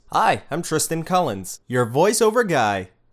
I'm your hip and friendly voice over guy
English - USA and Canada
Young Adult